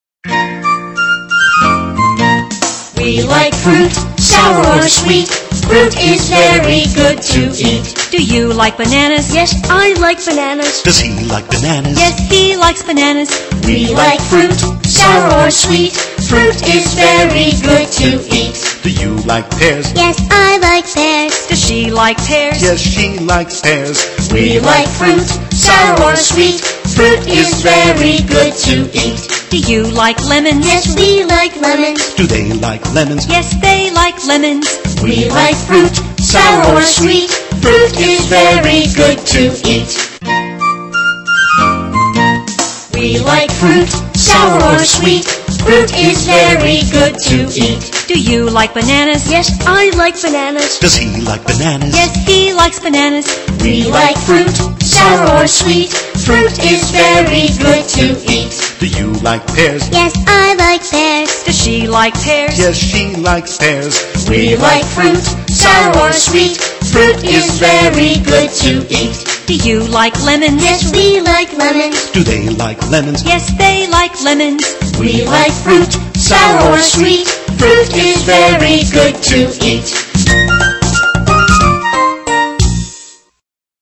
在线英语听力室英语儿歌274首 第245期:We like fruit的听力文件下载,收录了274首发音地道纯正，音乐节奏活泼动人的英文儿歌，从小培养对英语的爱好，为以后萌娃学习更多的英语知识，打下坚实的基础。